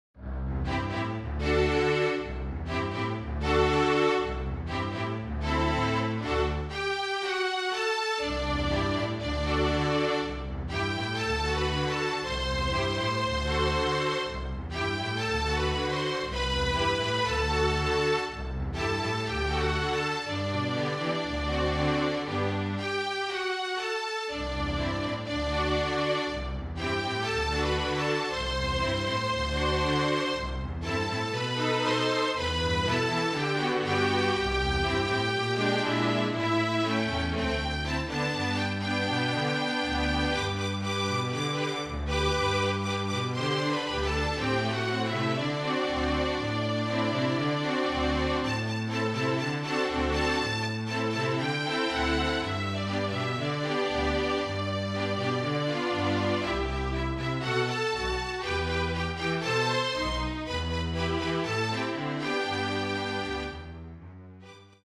FLUTE QUARTET
(Flute, Violin, Viola and Cello)
(Two Violins, Viola and Cello)
MIDI